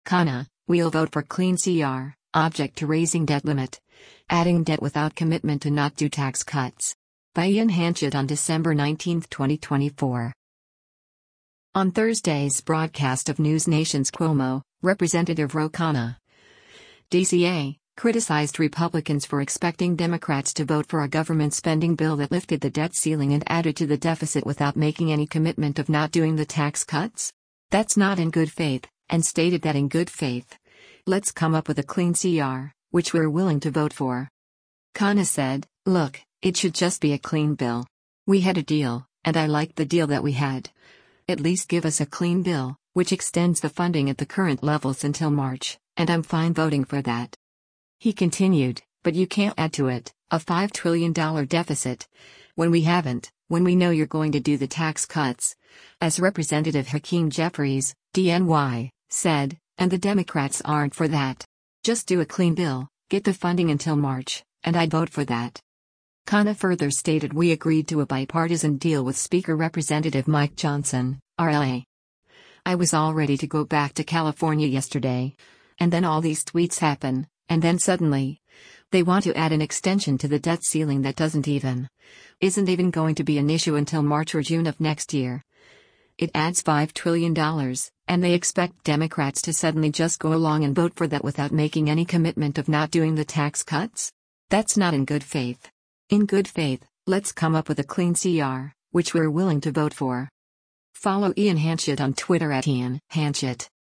On Thursday’s broadcast of NewsNation’s “Cuomo,” Rep. Ro Khanna (D-CA) criticized Republicans for expecting Democrats to vote for a government spending bill that lifted the debt ceiling and added to the deficit “without making any commitment of not doing the tax cuts? That’s not in good faith.”